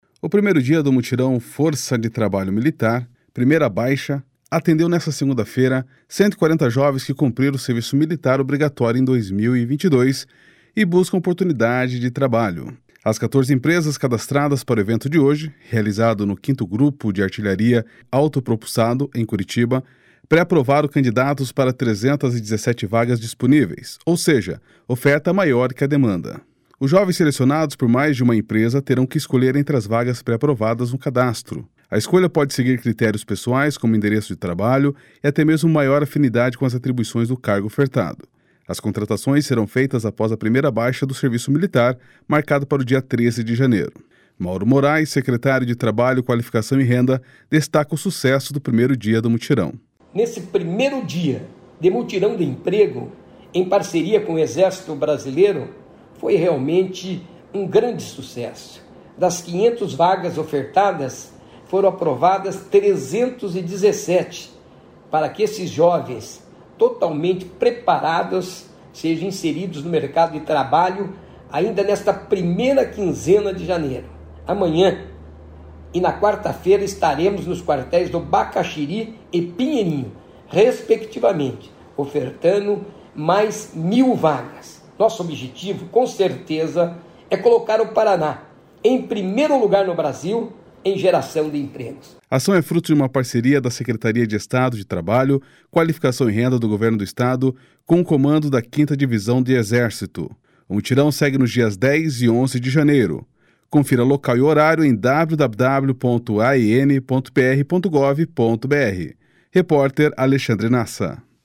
Mauro Moraes, secretário de Trabalho, Qualificação e Renda, destaca o sucesso do primeiro dia do mutirão. //SONORA MAURO MORAES//